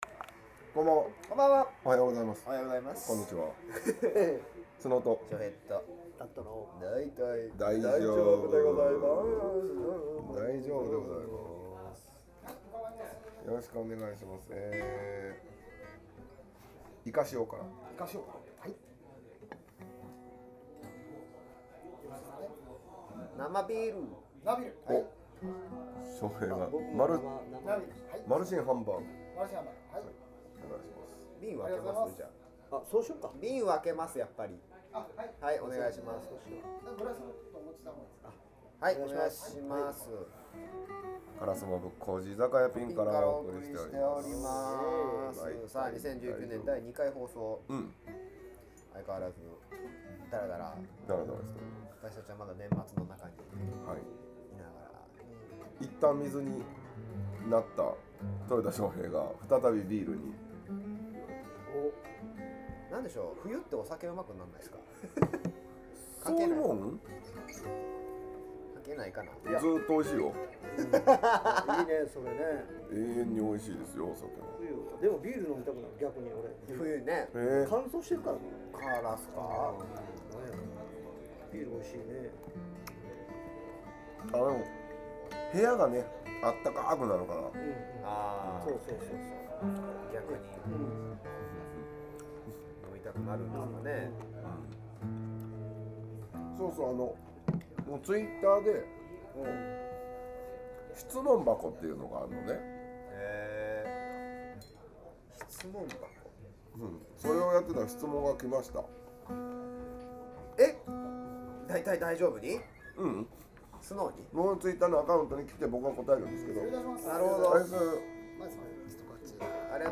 （１２月３日収録④）音楽以外の趣味を探しているおっさん３人でございます。